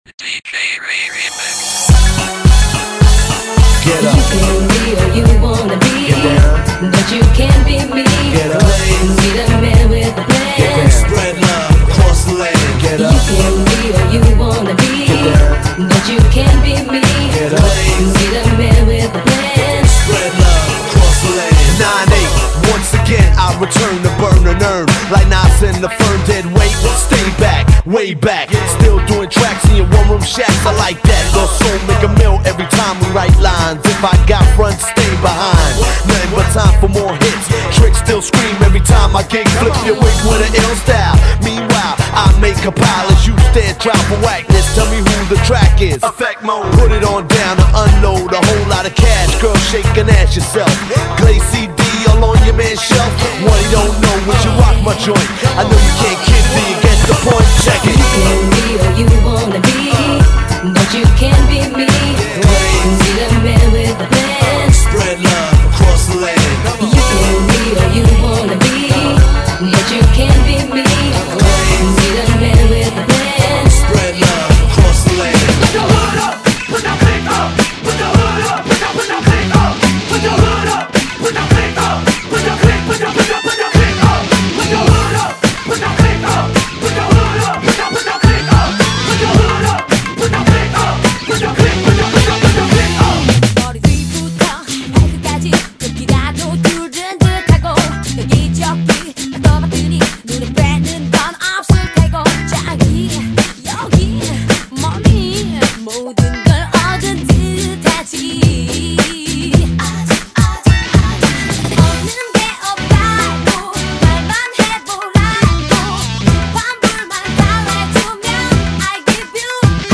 BPM107--1
Audio QualityPerfect (High Quality)